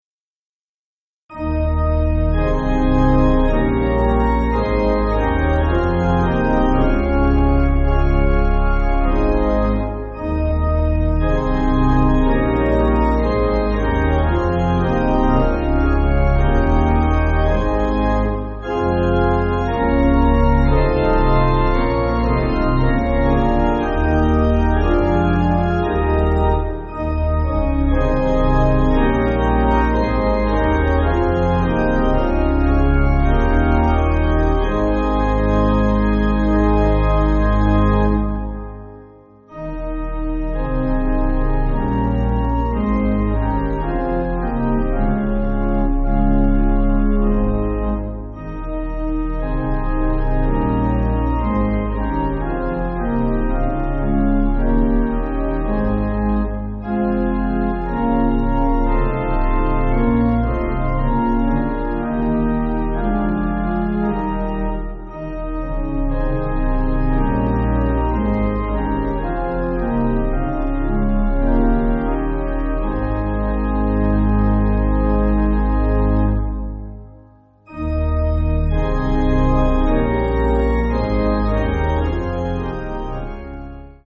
(CM)   5/Ab